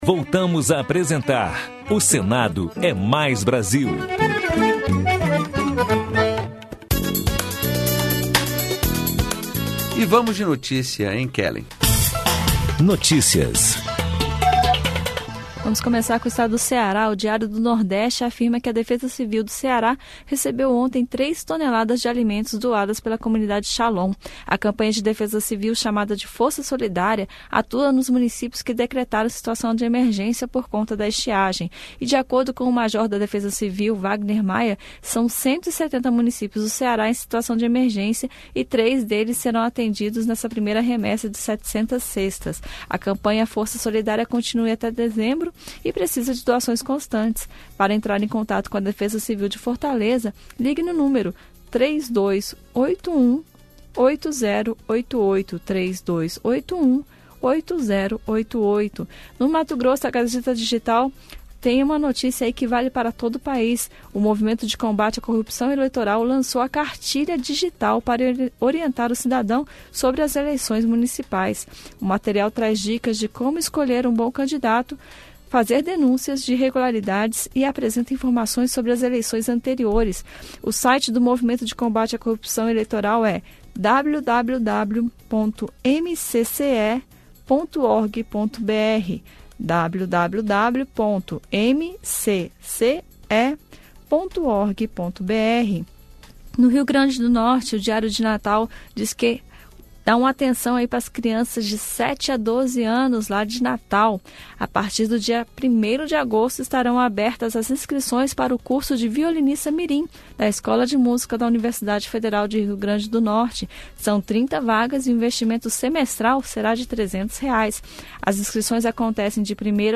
O Senado é Mais Brasil - 2º bloco Notícias: giro pelos estados Minuto Cultural: Fortaleza (CE) Jovem Senador: Série especial sobre o concurso de redação do Senado Federal. Reportagem explica como funciona o programa Minuto Cultural